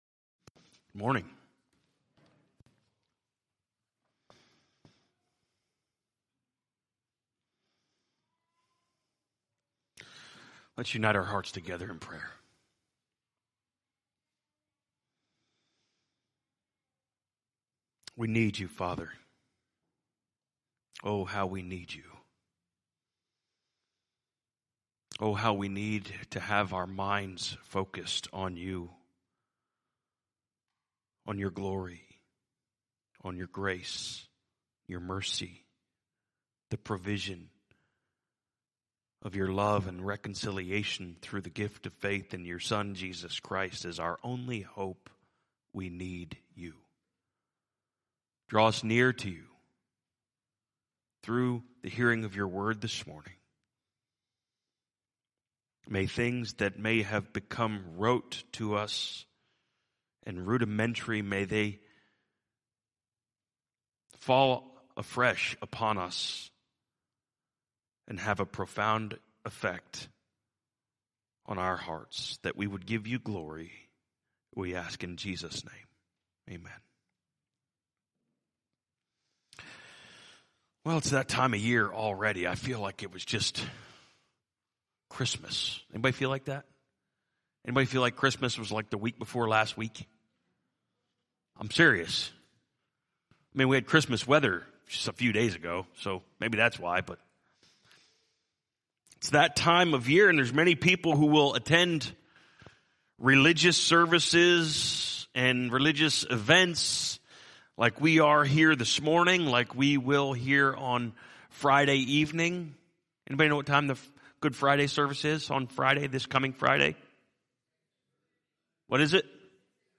From Series: "2022 Sermons"